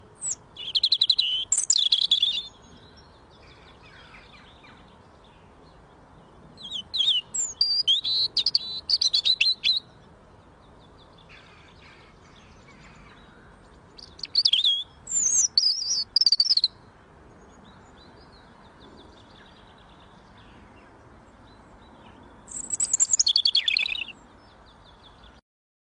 悦耳动听的知更鸟叫声